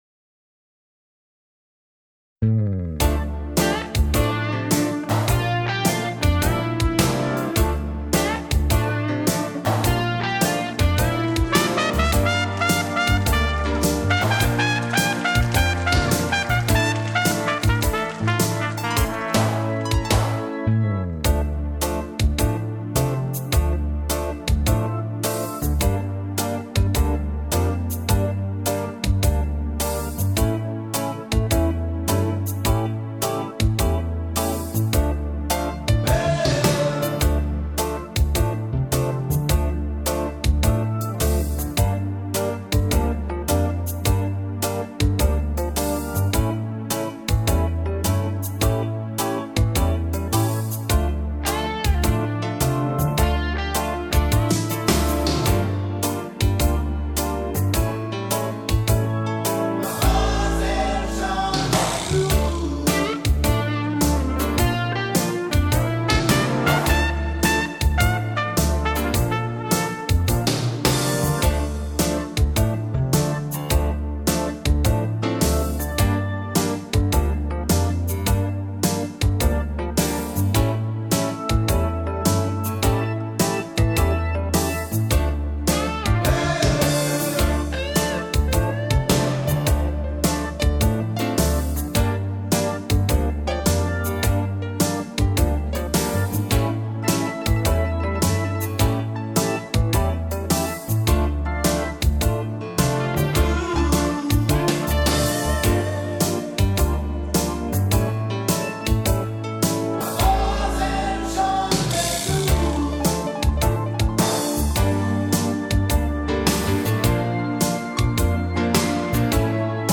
минусовка версия 101118